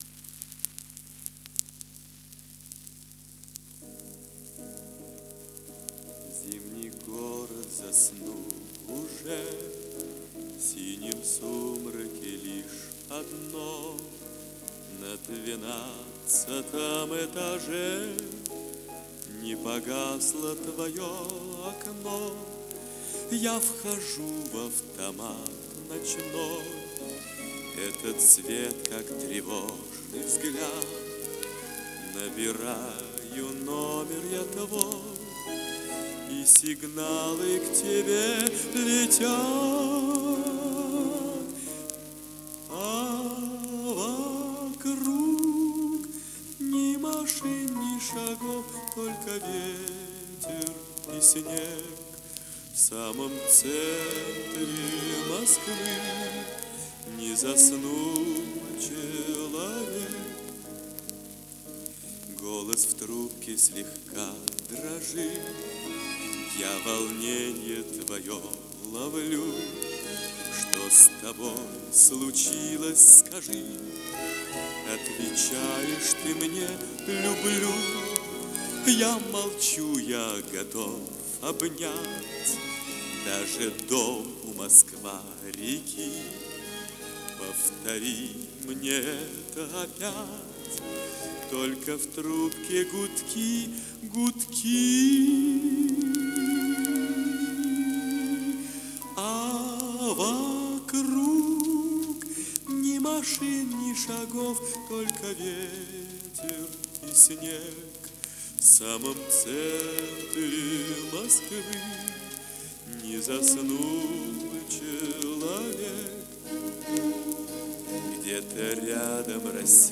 Оркестр . Перегон с грампластинки выполнен в 2022 году.